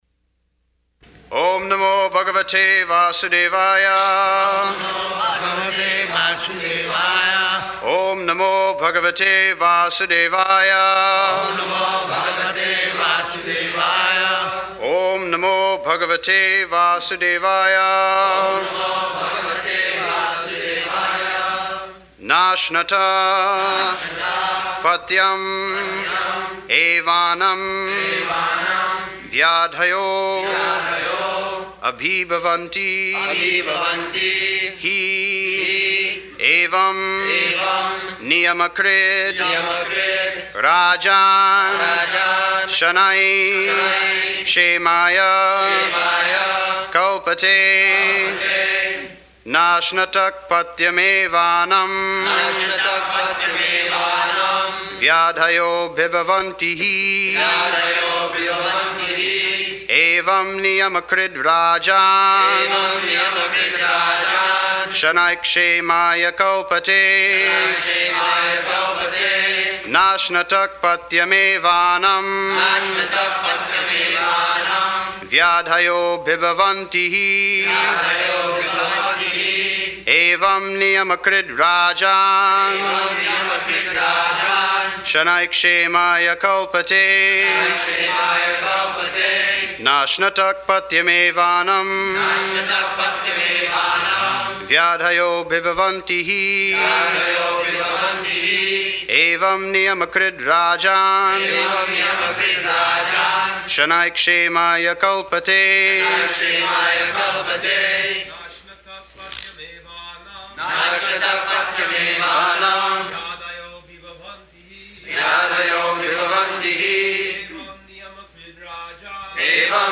-- Type: Srimad-Bhagavatam Dated: June 25th 1975 Location: Los Angeles Audio file
[Prabhupāda and devotees repeat] [leads chanting of verse, synonyms, etc.]
[Prabhupāda corrects pronunciation of annam when devotees chant]